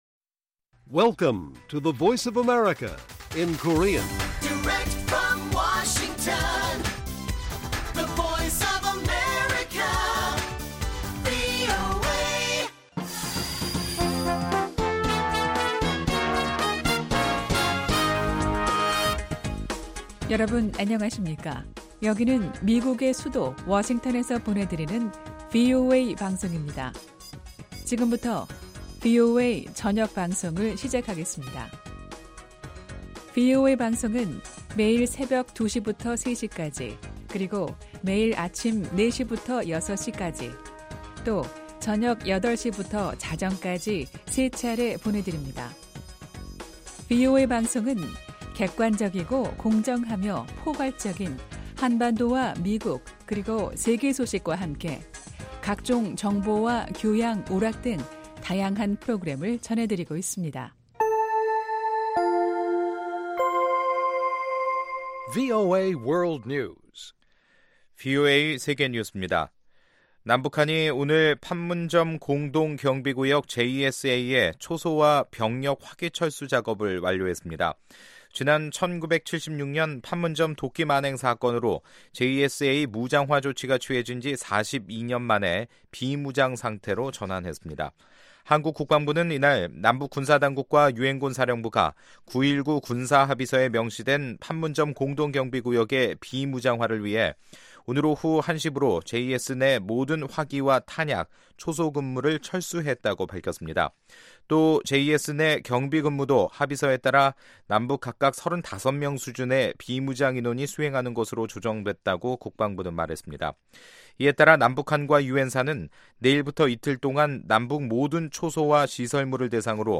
VOA 한국어 간판 뉴스 프로그램 '뉴스 투데이', 2018년 10월 25일 1부 방송입니다. 미국 국무부는 북한, 중국, 러시아가 유엔에 대북제재 완화를 촉구하는 움직임과 관련해, 이는 비핵화 이후 이뤄질 것이라는 입장을 재확인했습니다. 유엔군사령부가 한반도의 최근 변화 상황에서 자신들의 기여를 평가하며, 앞으로도 역할을 하겠다고 밝혔습니다.